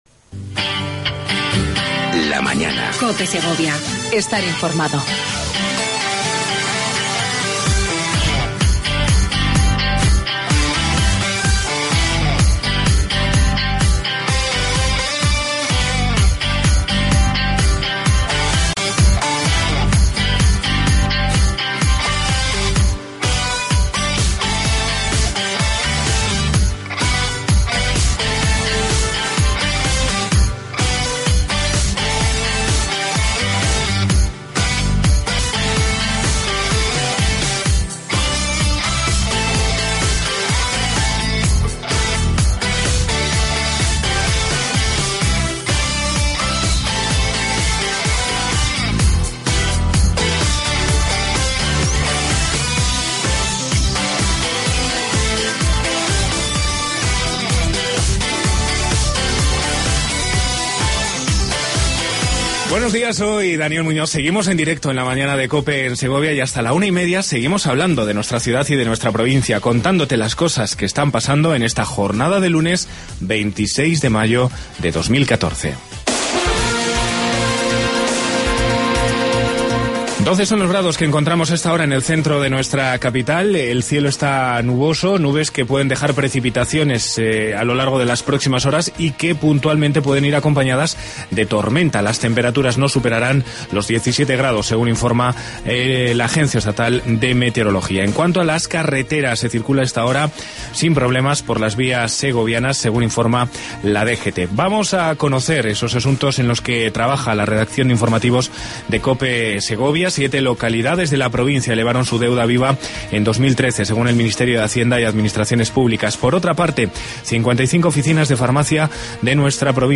AUDIO: Entrevista con Pilar Sanz, subdelegada de gobierno en Segovia.